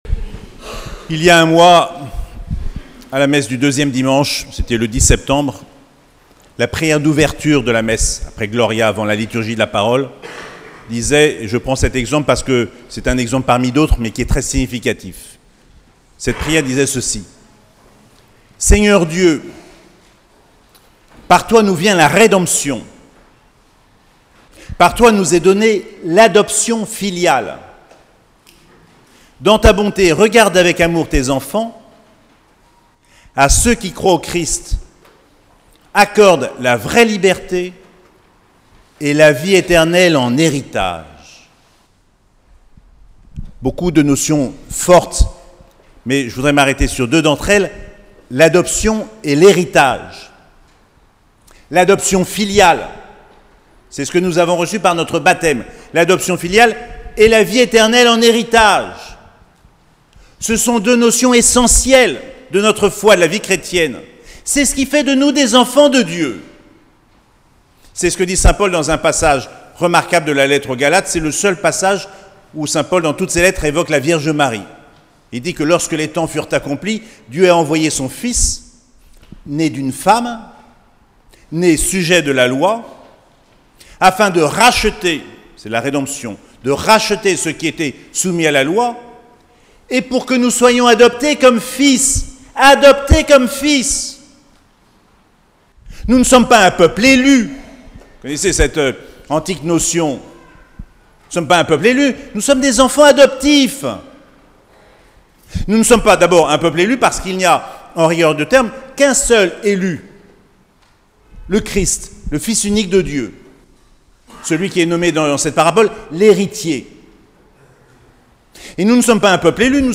27ème dimanche du temps ordinaire - 8 octobre 2023